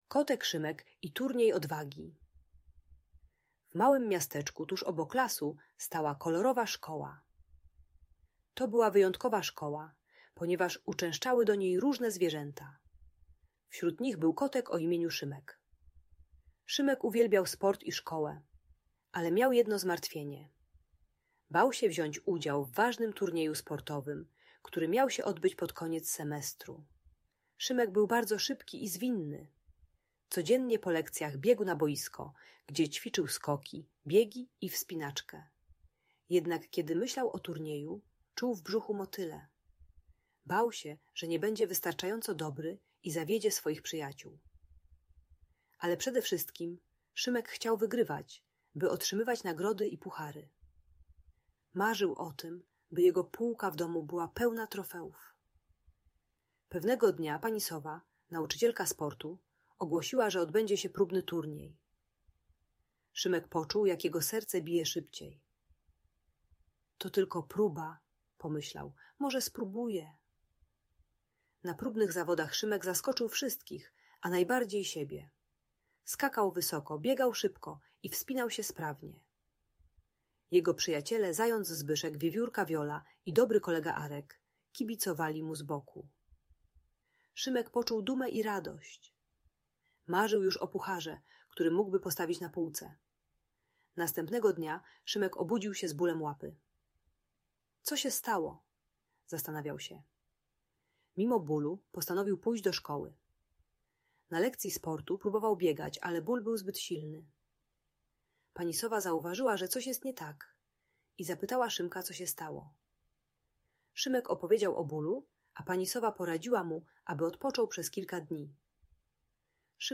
Historia Kotek Szymek i Turniej Odwagi - Audiobajka